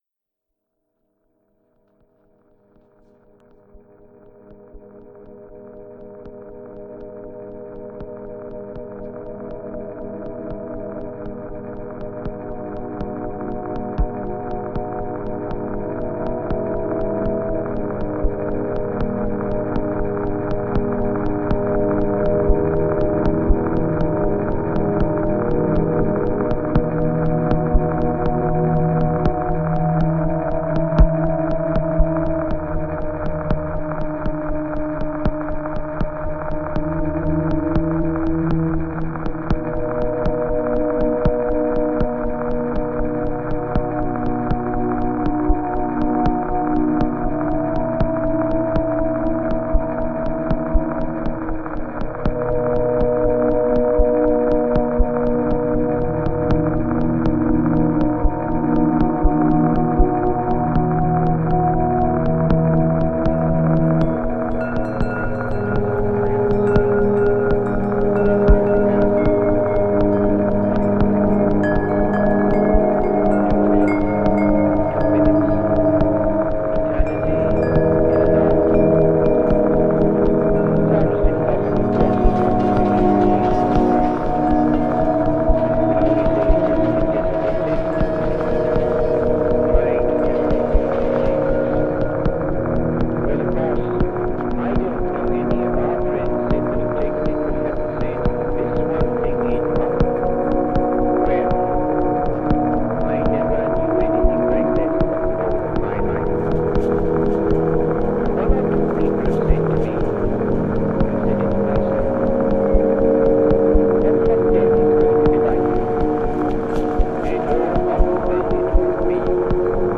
Genre: Ambient/Deep Techno/Dub Techno.